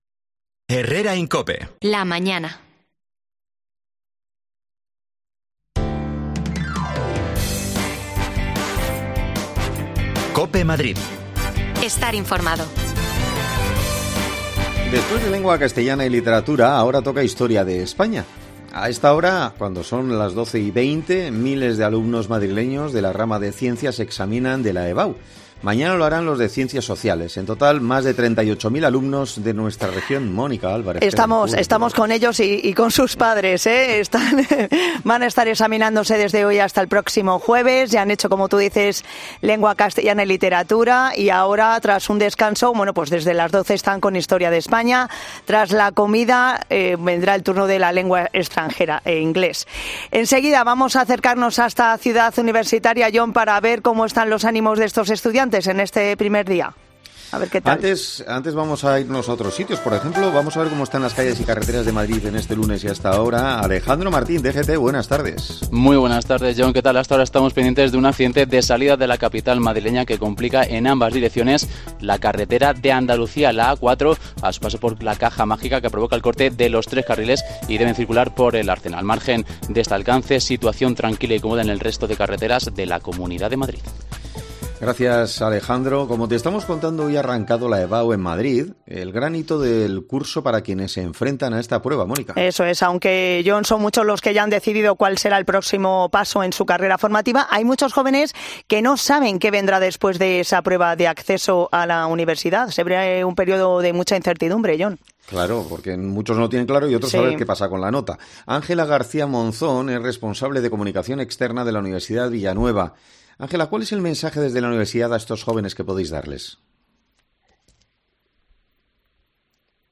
Nos acercamos hasta la Ciudad Universitaria para hablar con algunos de los protagonistas de hoy